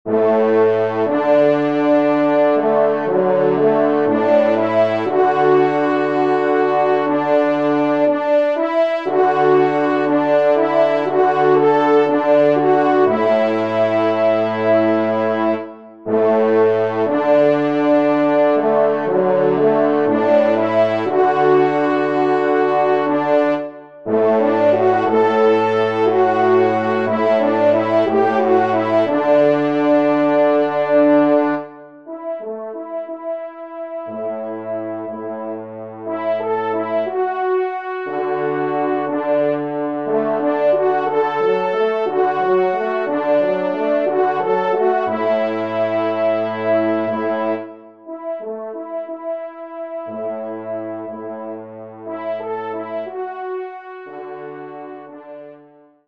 Genre :  Divertissement pour Trompes ou Cors en Ré
4e Trompe